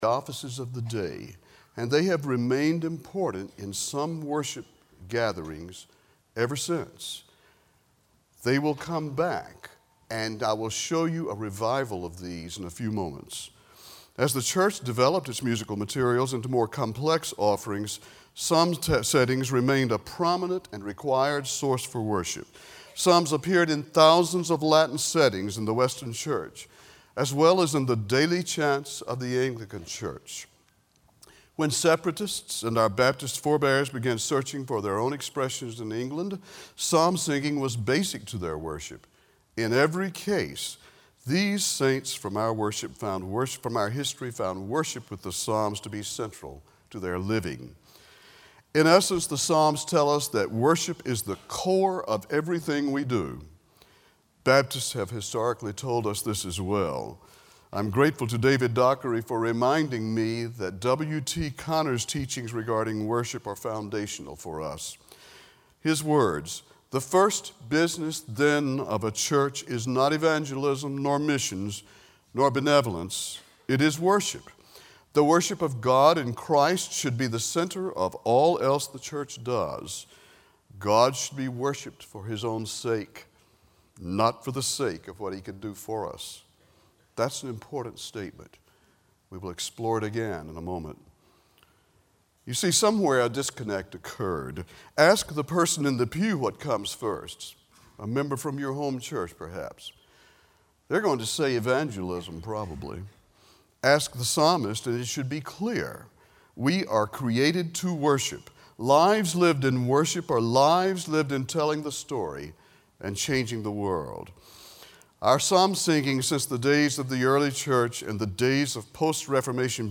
Chapel